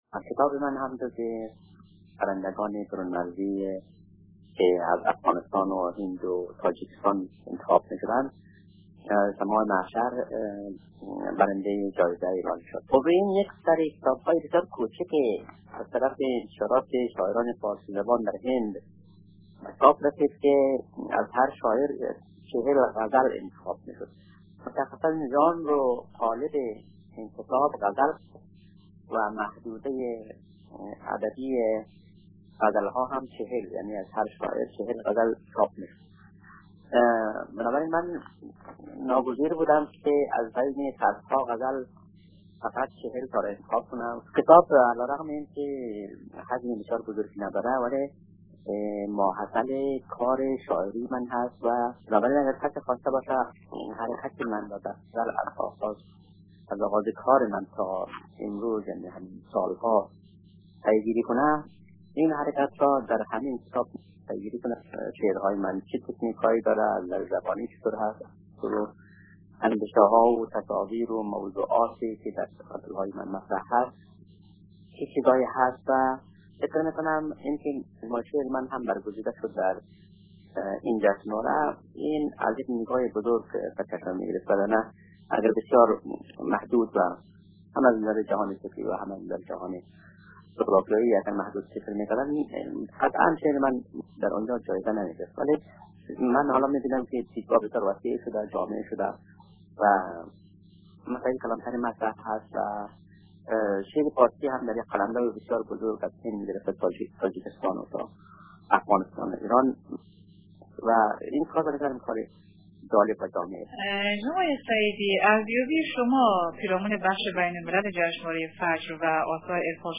گفت وگوی اختصاصی با رادیو دری